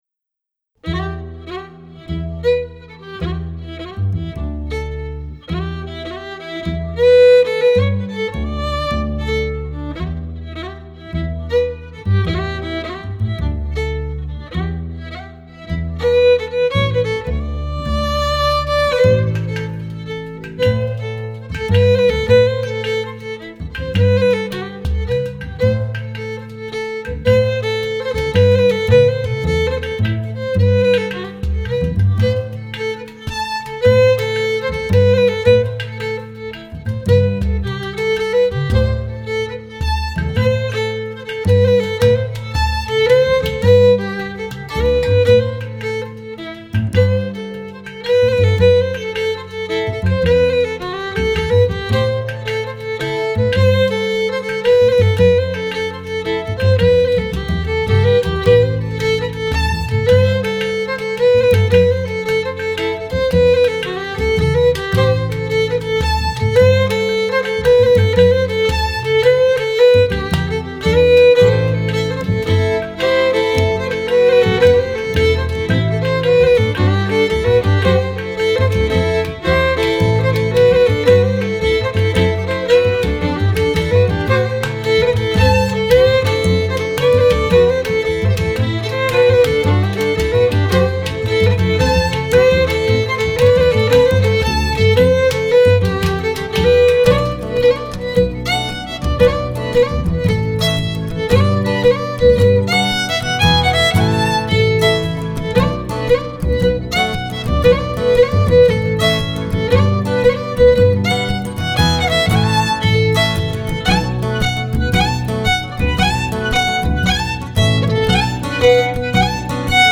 Scottish and Québécois fiddling.
à la guitare
au piano
aux percussions